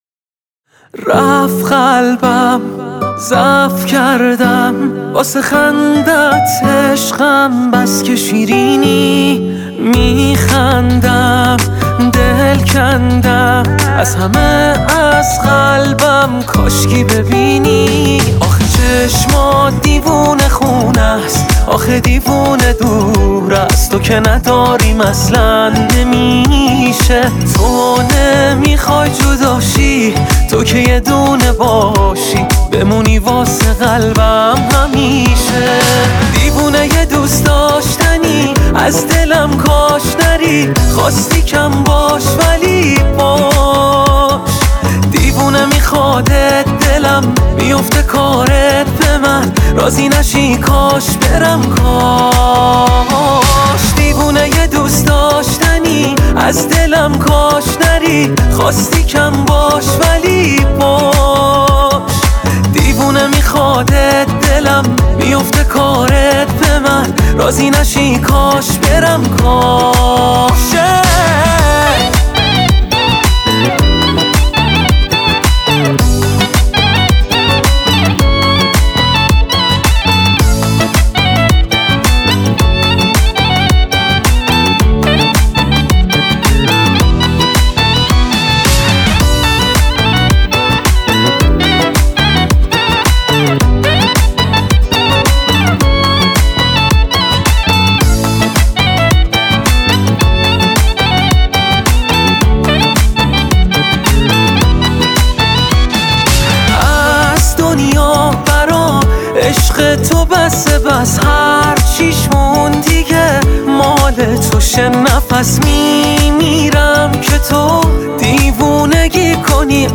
آهنگ شاد ، دانلود آهنگ0 نظر31 جولای 2023